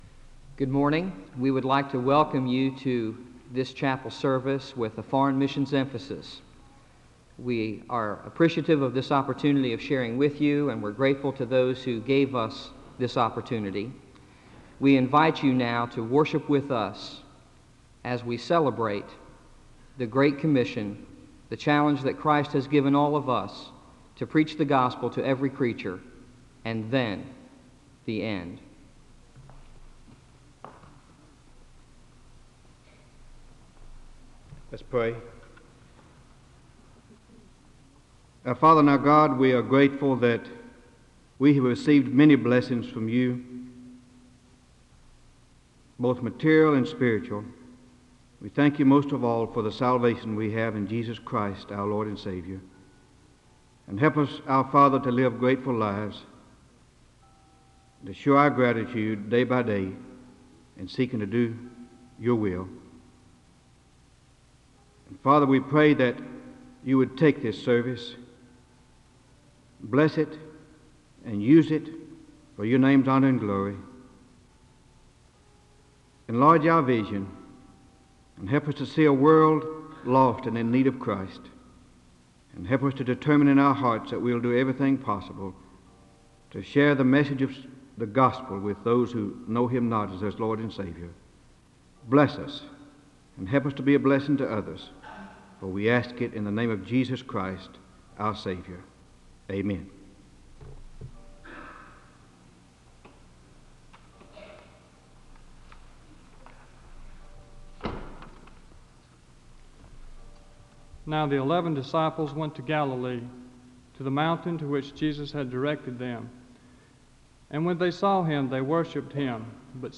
SEBTS Chapel and Special Event Recordings - 1970s